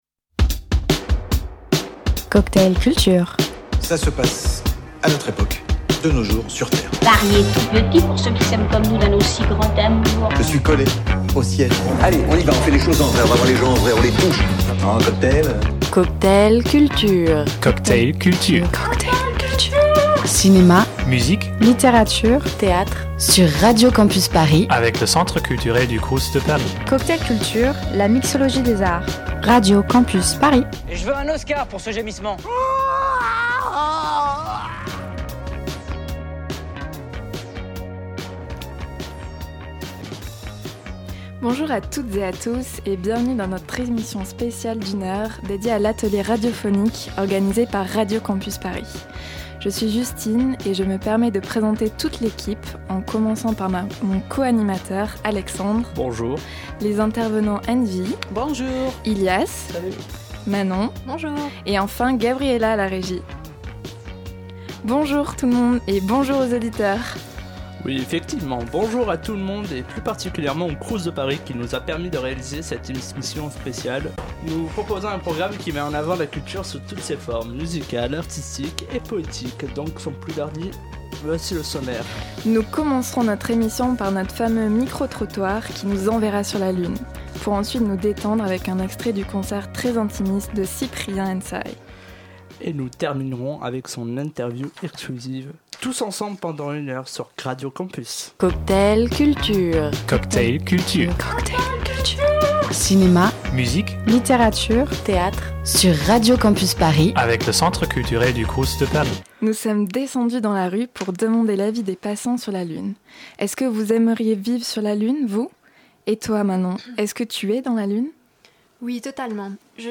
Retour au centre culturel du CROUS de Paris pour un deuxième volet de l'émission Cocktail Culture. Nouvelle équipe mais mêmes règles du jeu : Une émission dédiée à la culture étudiante!